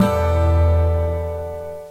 En lettere funky til PowerMac. (E-mol 11 spillet på guitar.)
PowerMacguitar.mp3